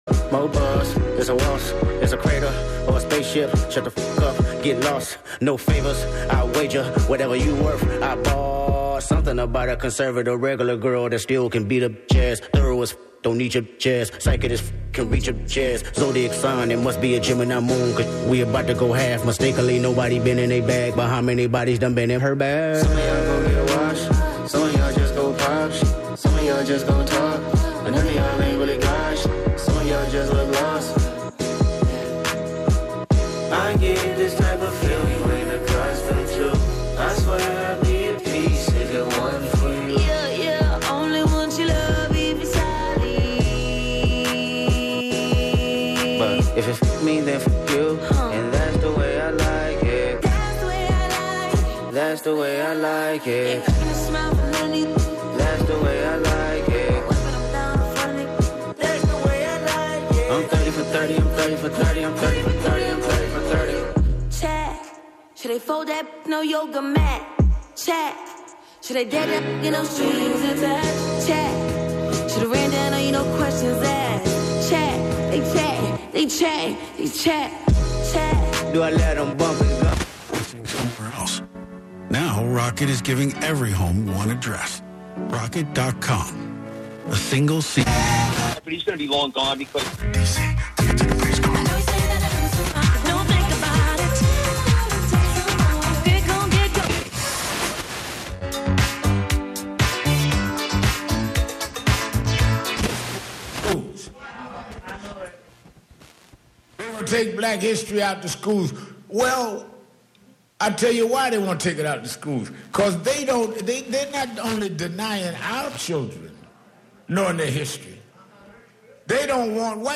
11am Live from Brooklyn, New York
making instant techno 90 percent of the time
play those S's, T's and K's like a drum machine